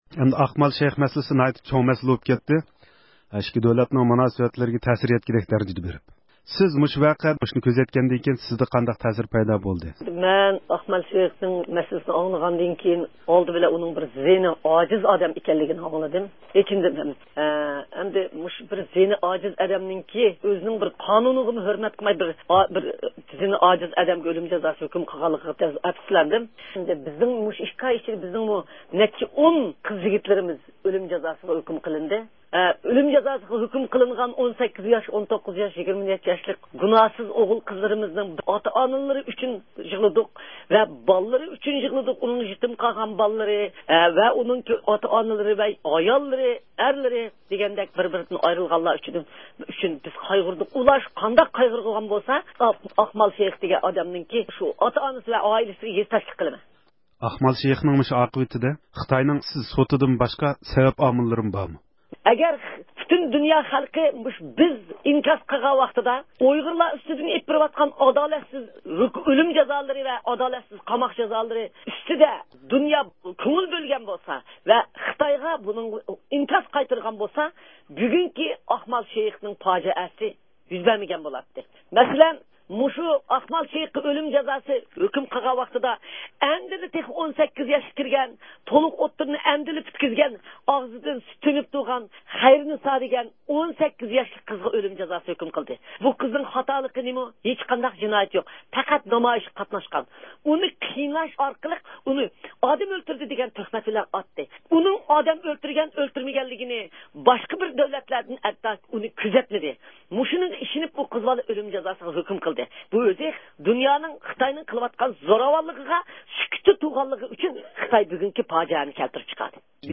رابىيە قادىر خانىم بىلەن ئەنگىلىيە ۋەتەندىشى ئاقمال شەيىخ ۋەقەسى ھەققىدە سۆھبەت – ئۇيغۇر مىللى ھەركىتى
بۈگۈن ئۇيغۇر مىللىي ھەركىتىنىڭ رەھبىرى رابىيە قادىر خانىم، ئاقمال شەيىخ ۋەقەسى ھەققىدە رادىيومىزغا تەسىراتلىرىنى بايان قىلدى. رابىيە خانىم سۆزىدە، ئۇيغۇرلارغا بېرىلىۋاتقان ئۆلۈم جازالىرى بىلەن ئاقمالغا بېرىلگەن ئۆلۆم جازاسى ئارىسىدىكى سەۋەپ – نەتىجە مۇناسىۋىتى ھەققىدە توختالدى.